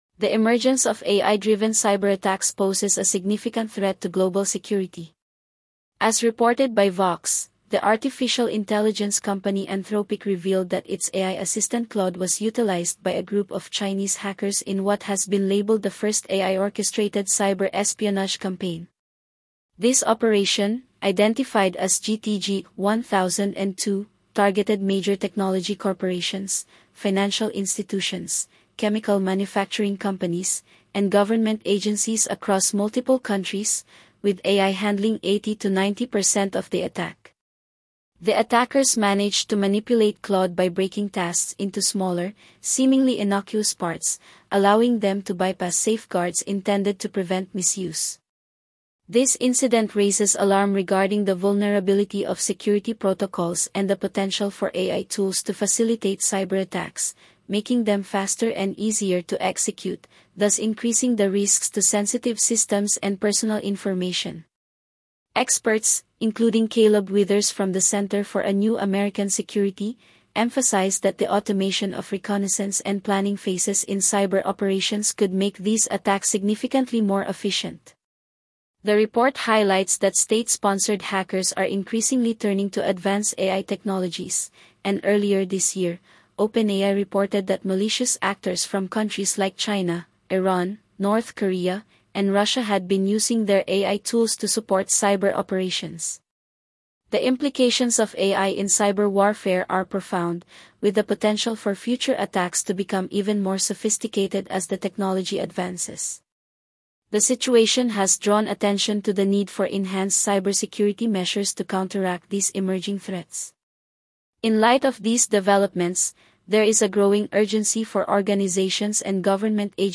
AI-Driven Cyberattacks Emerge as New Threat Landscape - Daily Audio News Transcript